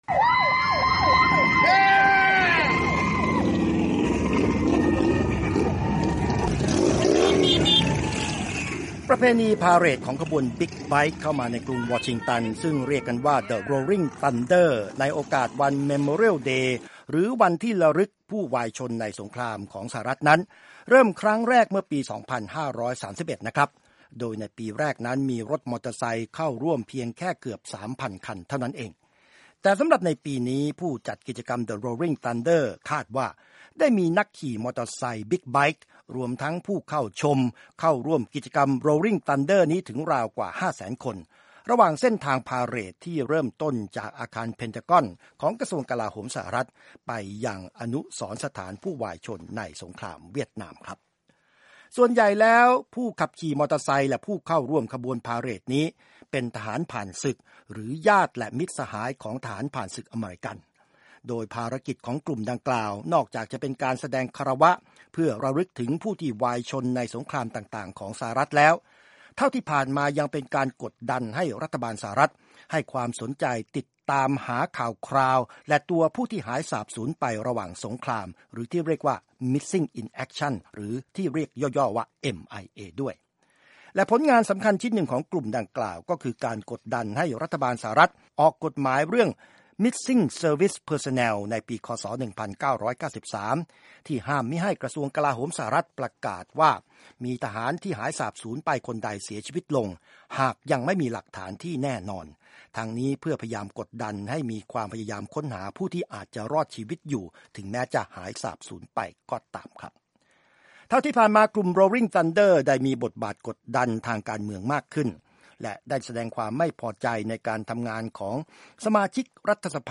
ขบวนจักรยานยนต์ Rolling Thunder ส่งเสียงก้องกรุงวอชิงตันเพื่อระลึกถึงเชลยศึกและผู้สูญหายในสงคราม